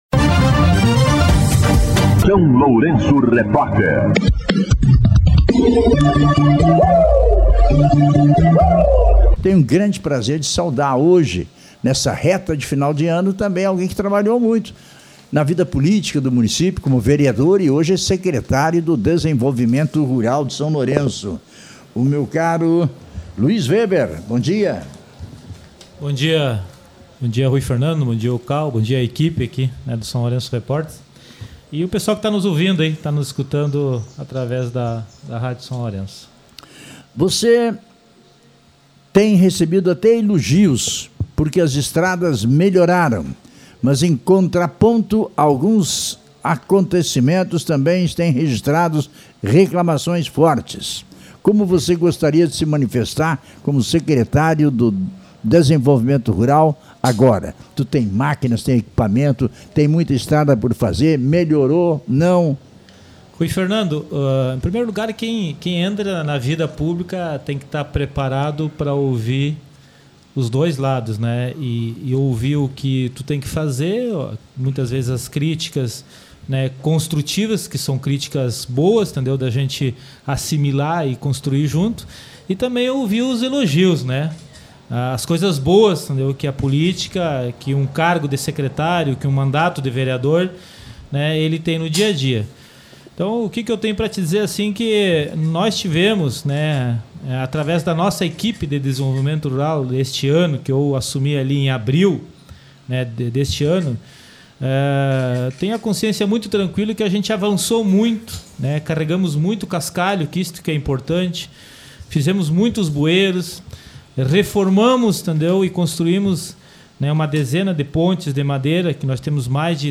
Entrevista com o secretário do Desenvolvimento Rural, Luis Weber
O secretário do Desenvolvimento Rural, Luis Weber, esteve no SLR RÁDIO nesta terça-feira (30) para falar sobre o caminhão que caiu após o colapso de uma ponte em Santa Isabel, na última semana, e os possíveis impactos ambientais decorrentes do acidente.
Entrevista-30.12-secretario-LUIZ-WEBER.mp3